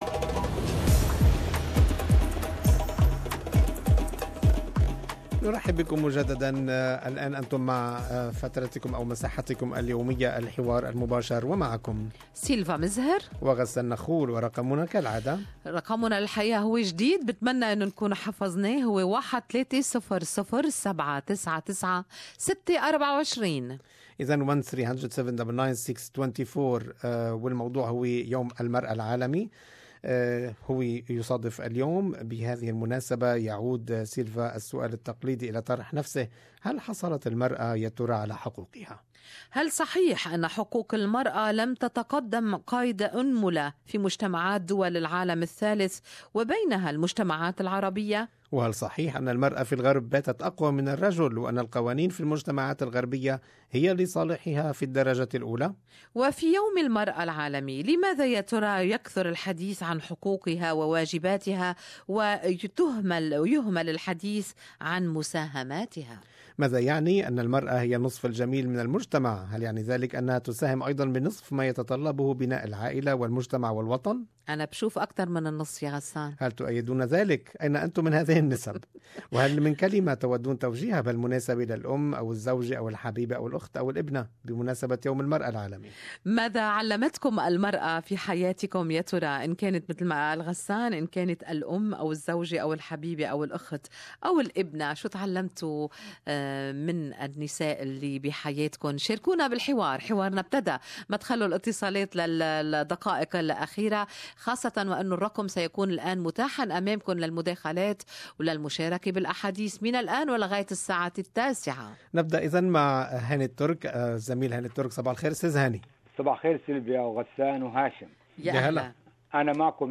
اراء المستمعين في فقرة الحوار المباشر